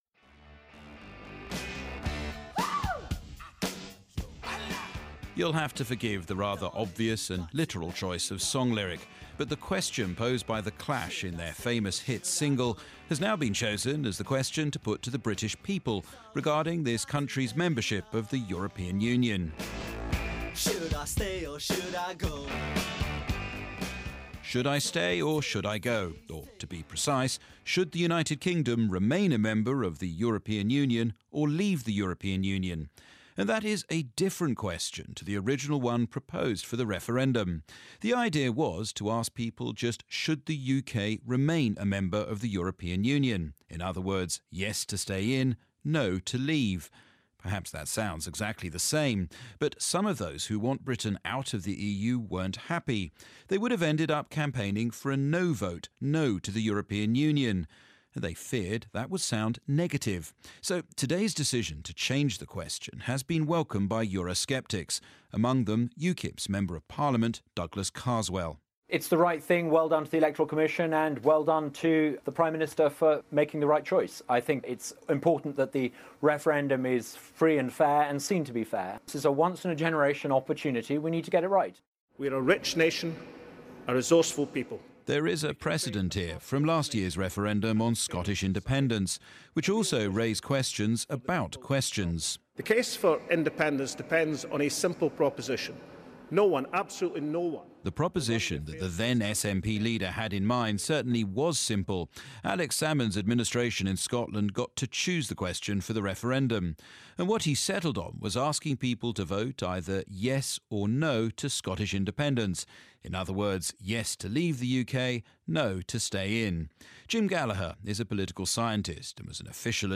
Here's my report on why the form of question you ask can change the answer you get.